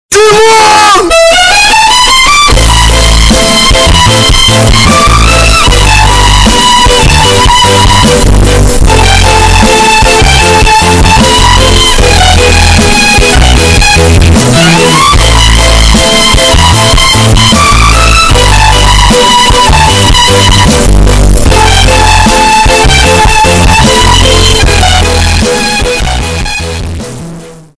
мощные басы
скрипка